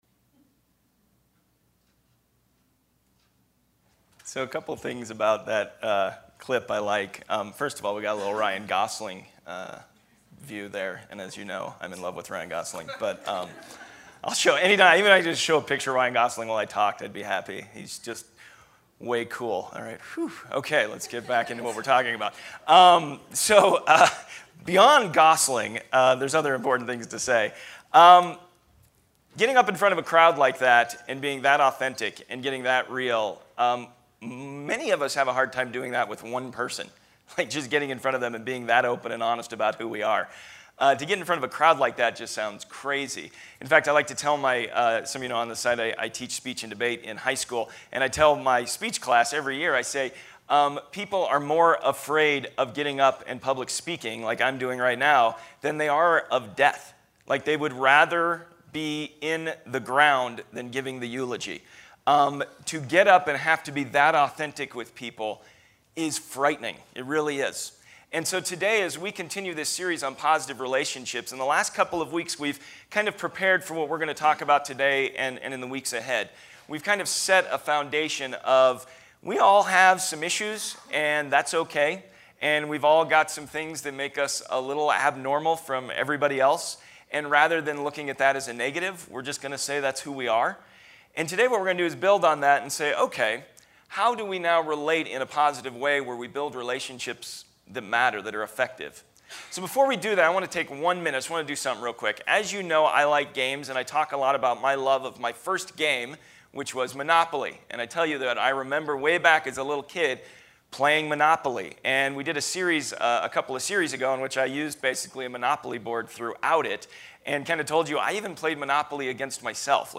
Take a listen to this teaching on “Finding Authenticity”. We talked about the human condition of feeling shame, hiding, being afraid, and ultimately blaming. We discussed how we can come out of that in finding ourselves as new creations in Christ, desiring relationships of authenticity with God and others.